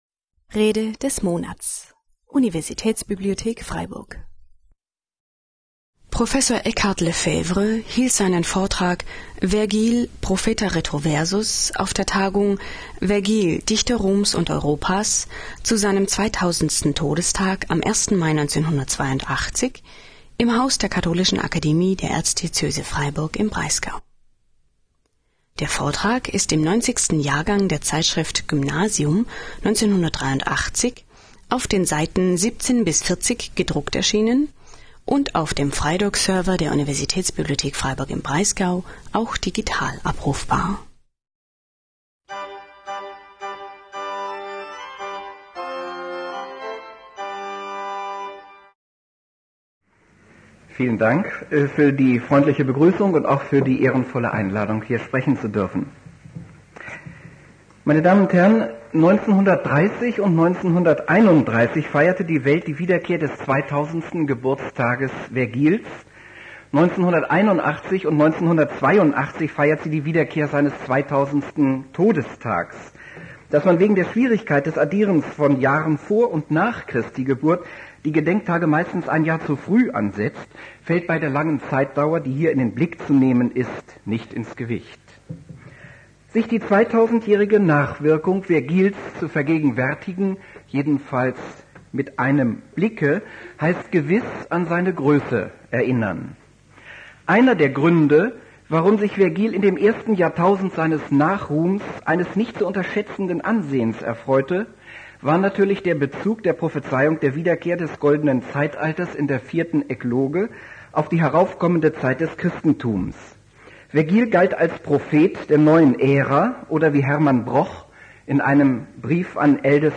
Zu seinem 2000. Todestag am 1. Mai 1982 im Haus der Katholischen Akademie der Erzdiözese Freiburg im Breisgau.